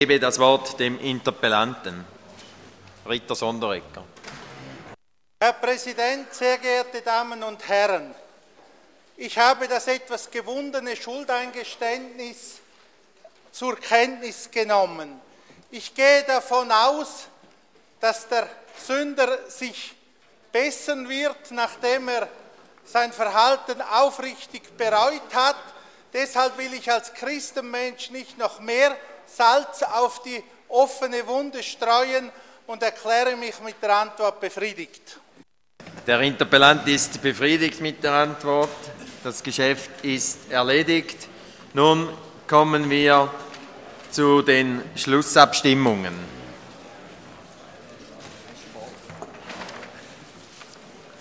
27.2.2013Wortmeldung
Session des Kantonsrates vom 25. bis 27. Februar 2013